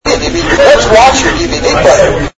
Through the iPad's Bluetooth, which stays active even after sleep/wakeup, my iPad Speaker increases volume about 200%.
Here are 2 recordings that I made in a fast food restaurant.
The only difference between these recordings is my iPad Speaker with its volume up.
recorded-speaker.mp3